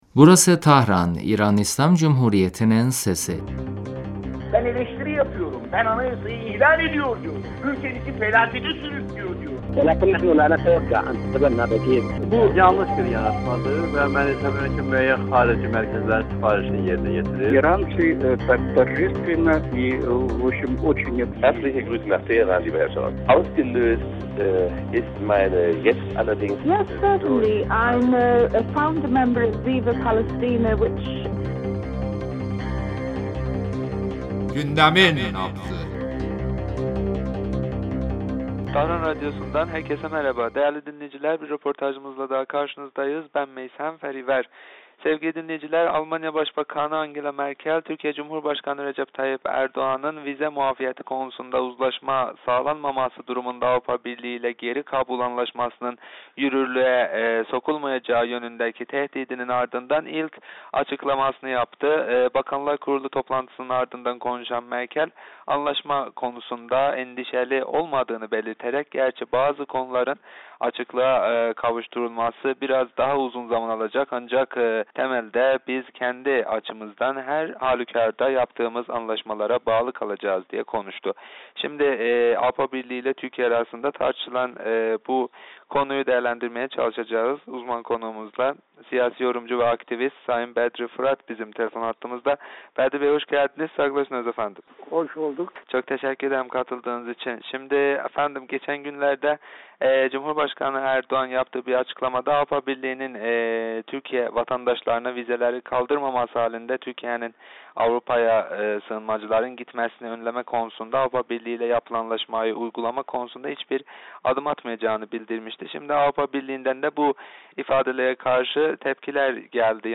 radyomuza verdiği demecinde AB ile Türkiye arasında tartışılan mültecilik meselesi üzerinde görüşlerini bizimle paylaştı.